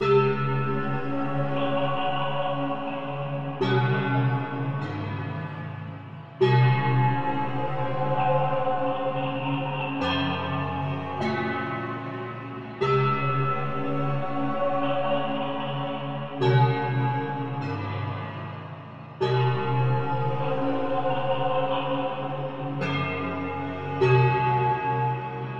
畸变的钟声 150 Dbm
描述：调性：Cmin 速度：150bpm 实验性
Tag: 150 bpm Weird Loops Bells Loops 4.31 MB wav Key : C